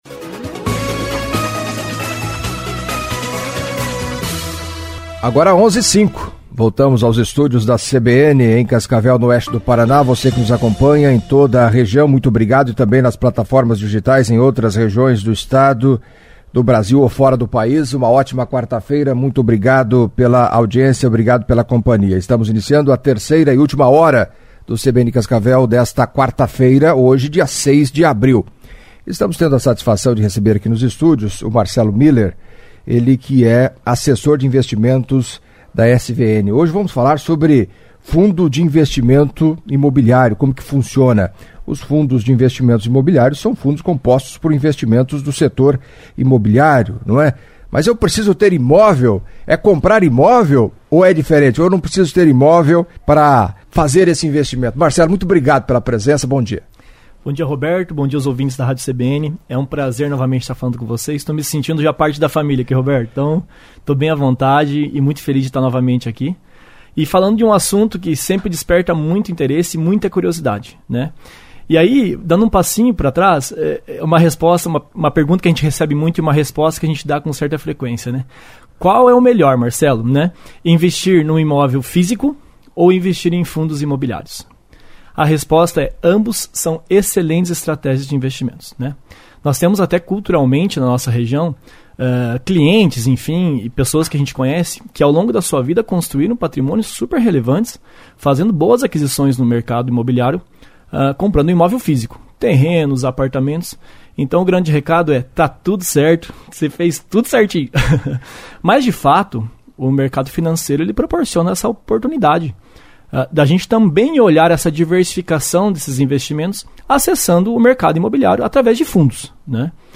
Em entrevista à CBN Cascavel nesta quarta-feira